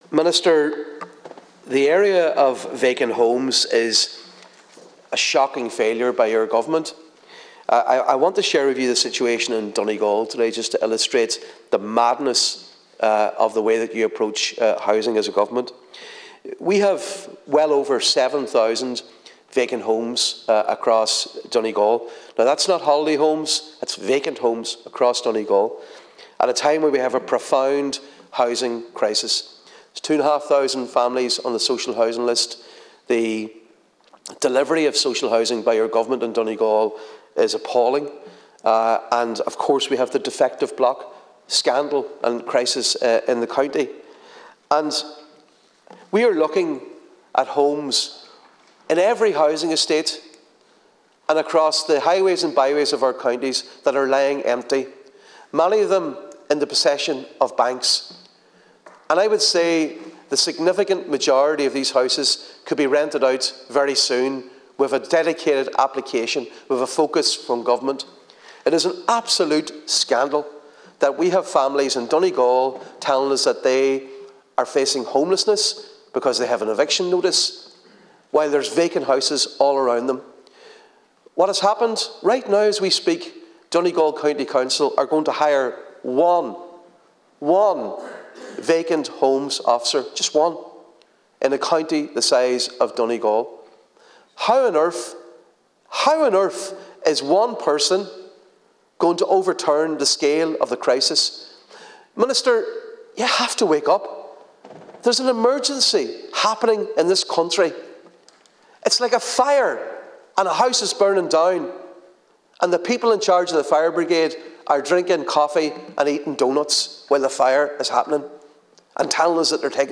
Speaking on a Social Democrats motion to increase the Vacant Properties Tax, Deputy MacLochlainn said approving just vacant homes officer for Donegal County Council is not an adequate response……………….